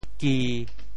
“基”字用潮州话怎么说？
基 部首拼音 部首 土 总笔划 11 部外笔划 8 普通话 jī 潮州发音 潮州 gi1 文 中文解释 基 <名> (形声。